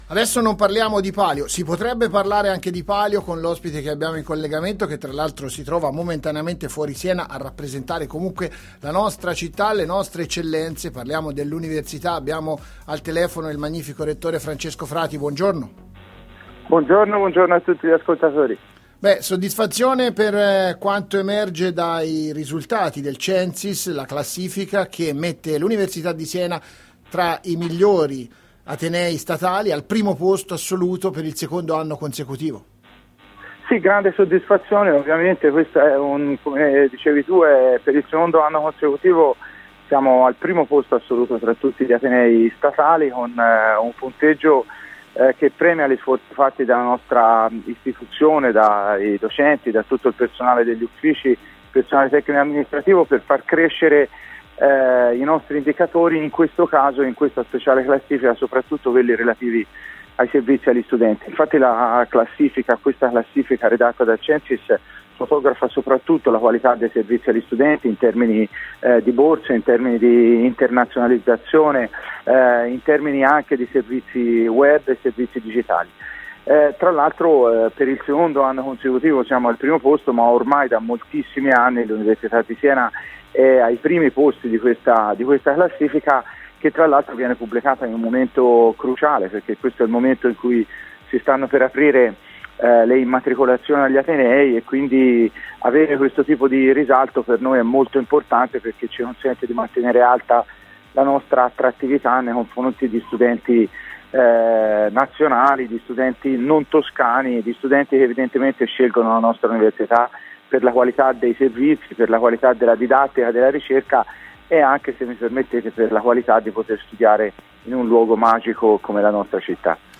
Interviste
L’università di Siena miglior ateneo statale d’Italia: il Rettore Francesco Frati 4 Luglio 2018